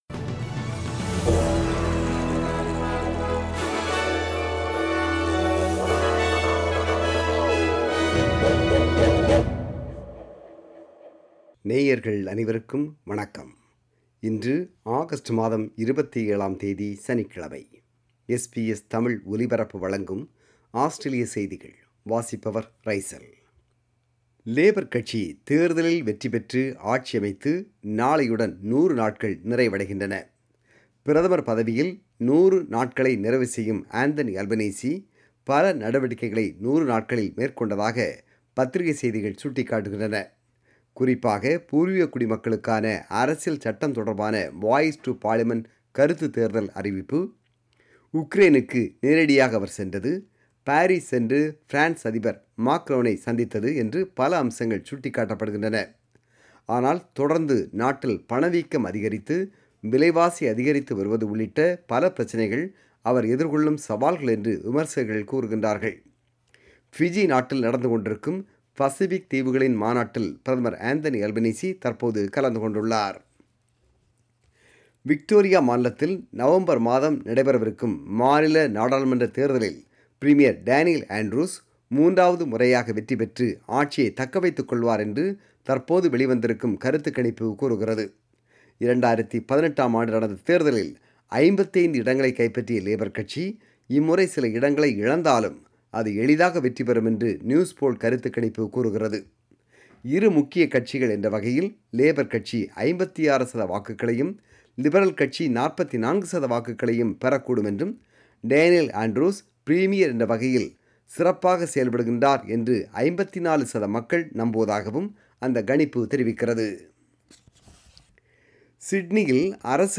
Australian News: 27 August 2022 – Saturday